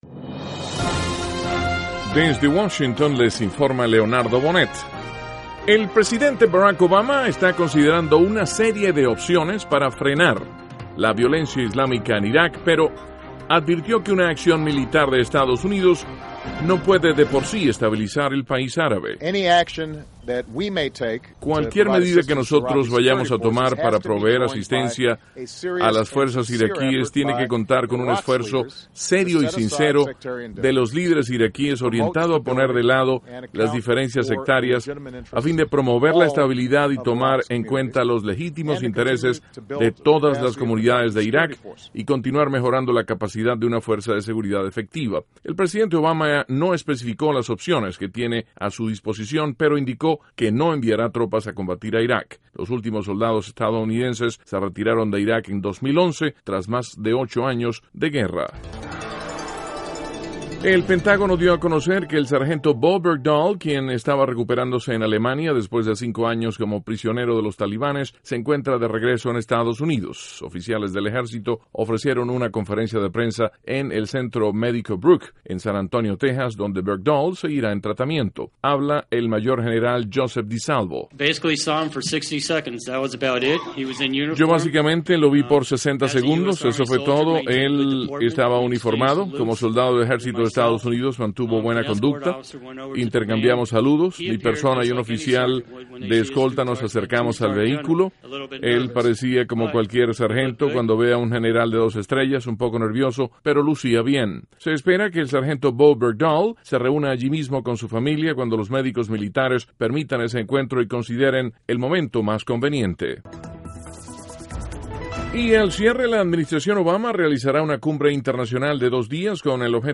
Duración: 2:55 Contenido: 1.- El presidente Obama afirma que no enviará tropas a Iraq y sugiere que los líderes iraquíes traten de lograr unidad política. (Sonido Obama) 2.- El Sargento Bergdhal se encuentra de regreso en Estados Unidos.